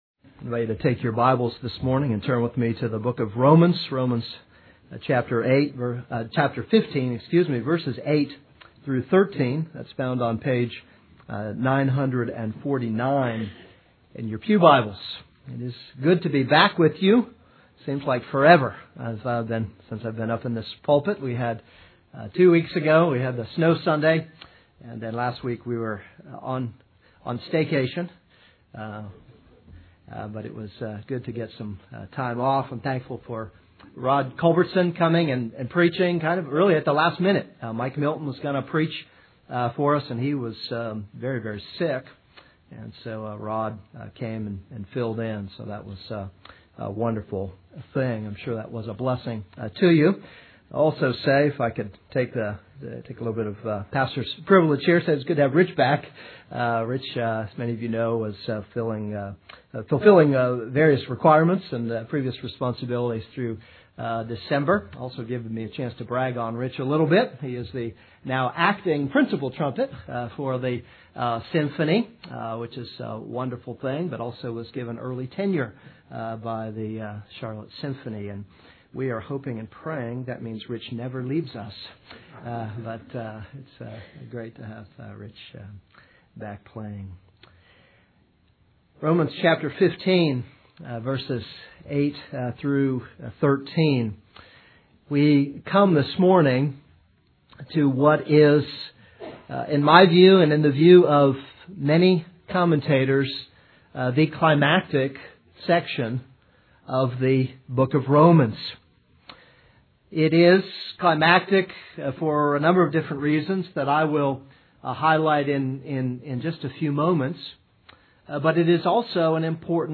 This is a sermon on Romans 15:8-13.